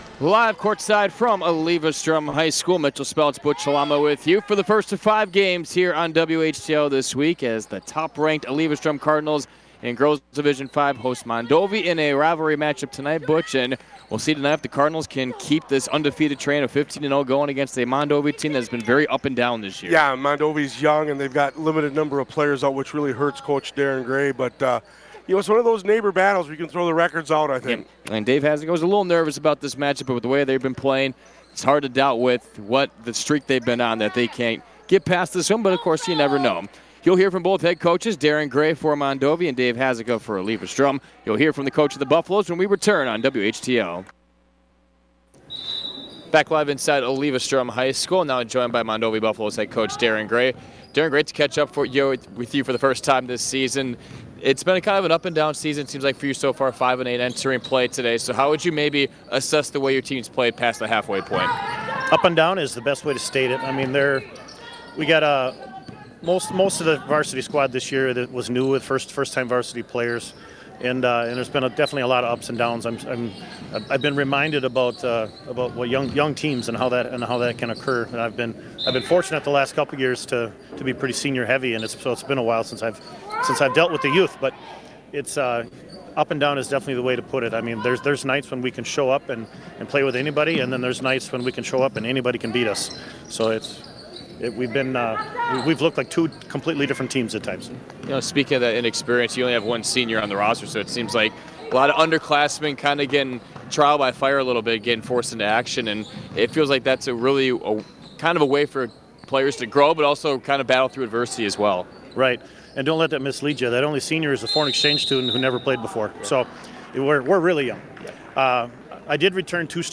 Western Wisconsin High School Sports Broadcast High School Girls State Championship Basketball Eleva-Strum vs Pachelli 3-14-26 Play Episode Pause Episode Mute/Unmute Episode Rewind 10 Seconds 1x Fast Forward 30 seconds 00:00 / Subscribe Share